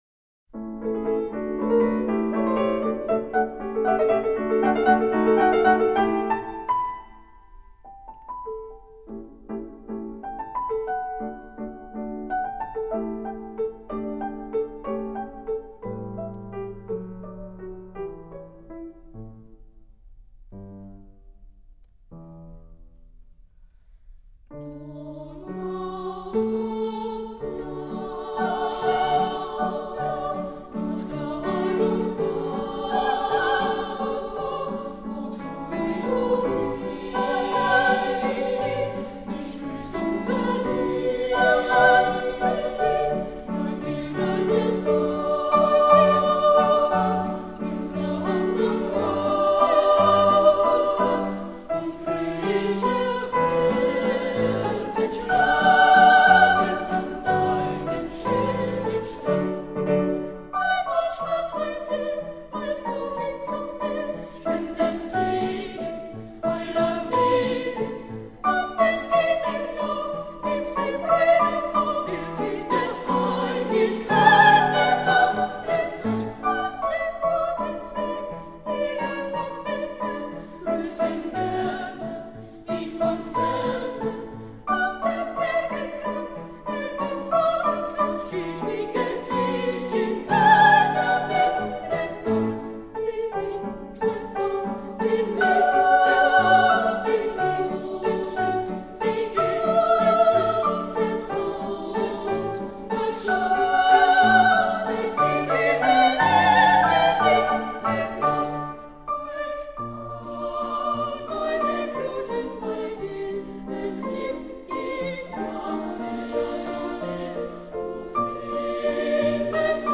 类型：古典
五百多年来，经历了战争、瘟疫，甚至哈布斯堡王朝的覆灭，唯一不变的是他们纯净无垢天使般的歌声。